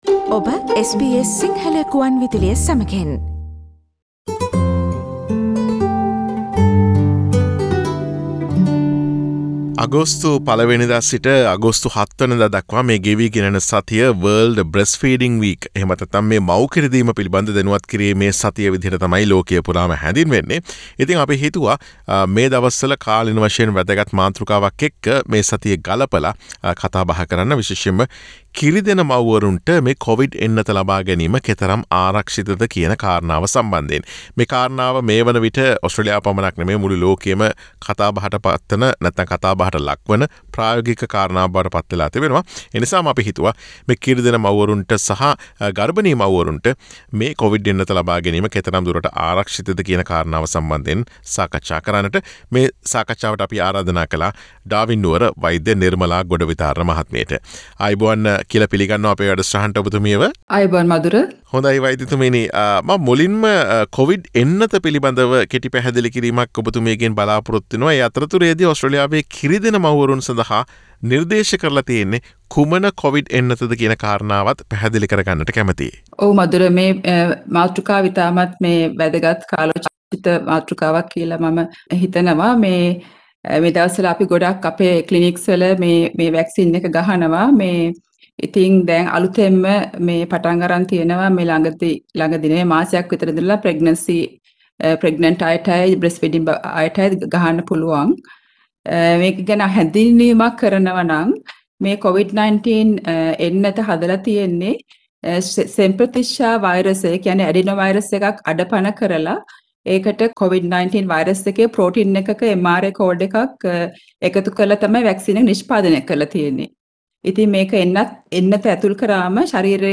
අගෝස්තු 1 සිට 7 දක්වා යෙදී ඇති World Breastfeeding Week හෙවත් මව් කිරි දීම පිළිබඳ දැනුවත් කිරීමේ සතිය නිමිතිකොටගෙන කිරිදෙන සහ ගර්භනී මව්වරුන්ට කොවිඩ් එන්නත ලබාගැනීම කෙතරම් ආරක්ෂිතද සහ එහි වාසි - අවාසි පිළිබඳ SBS සිංහල ගුවන් විදුලිය ගෙන එන සාකච්ඡාවට සවන් දෙන්න.